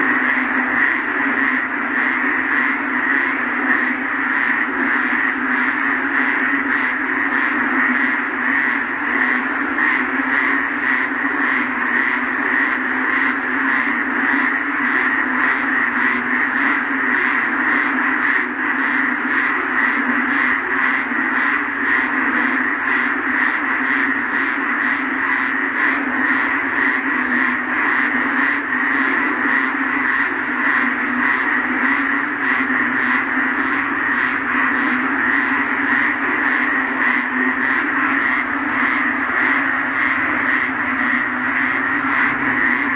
蛙声青蛙叫声蛙声一片
听青蛙叫声。
标签： 池塘 夏夜 蛙声
声道立体声